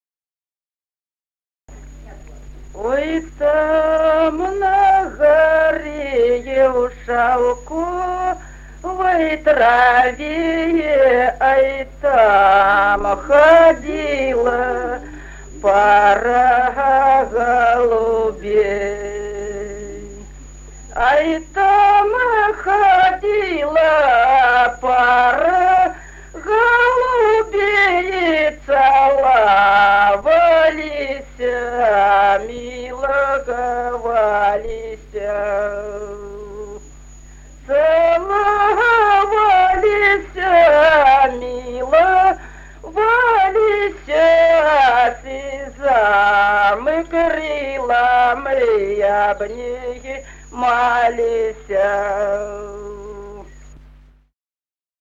| diskname = Песни села Остроглядово.